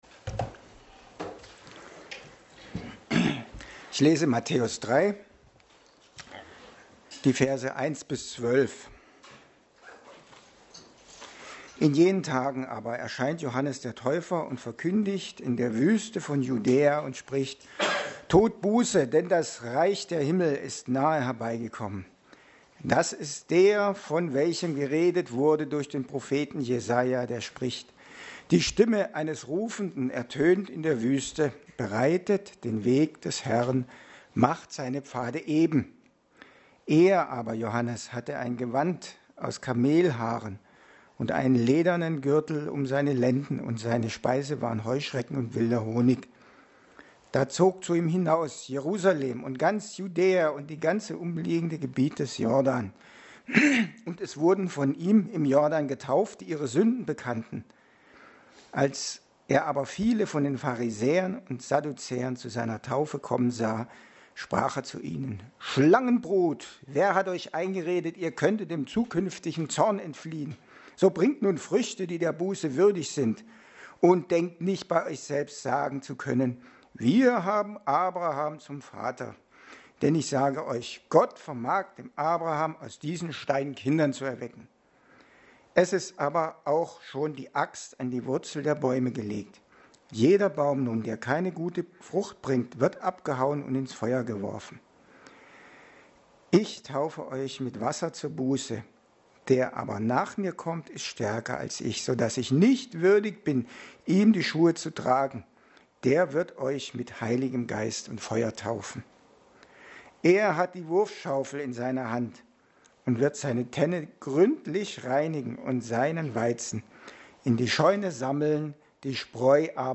Lukas 17,20-37 Dienstart: Predigt Sonstige Unerwartet … wundervoll